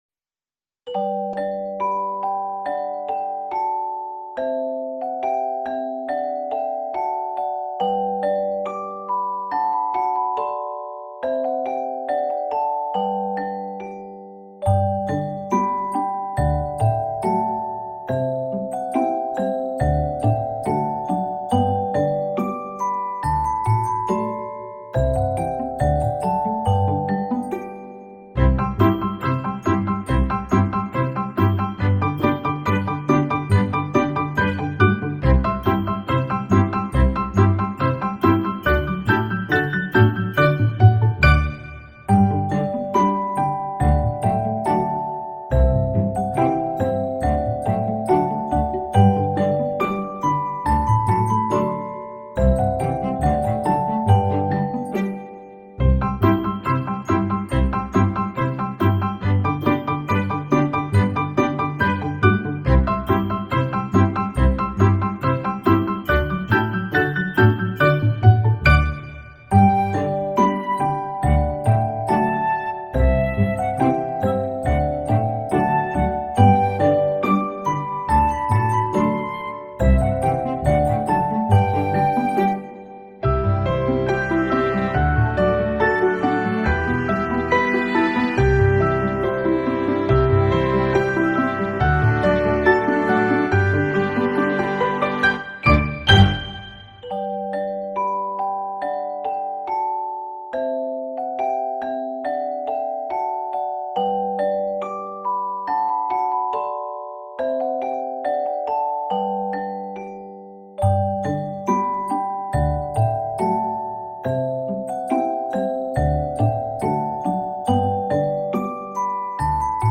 Música-de-relajación-para-niños-Música-para-relajarse-50k.mp3